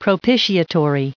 added pronounciation and merriam webster audio
1887_propitiatory.ogg